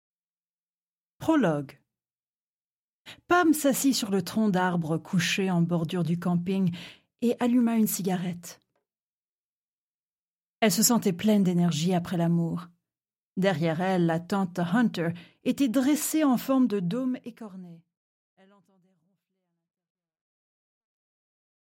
Аудиокнига Avant qu’il ne convoite | Библиотека аудиокниг
Прослушать и бесплатно скачать фрагмент аудиокниги